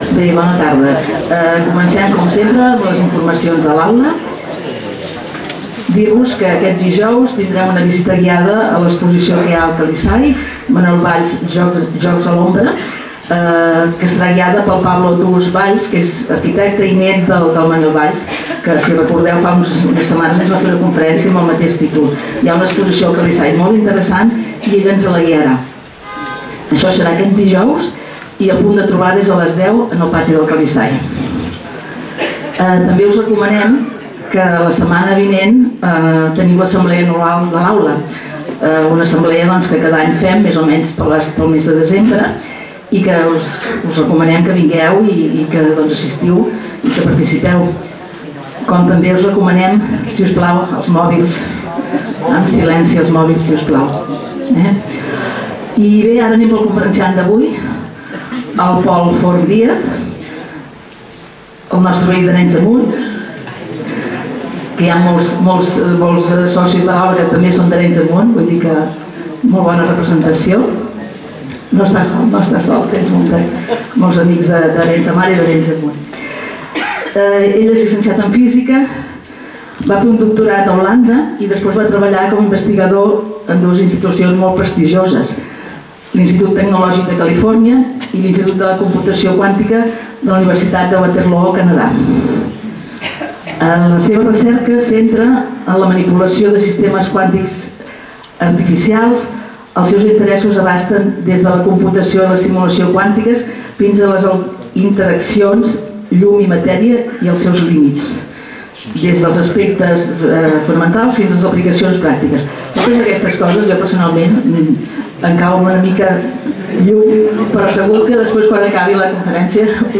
Lloc: Casal de Joventut Seràfica
Categoria: Conferències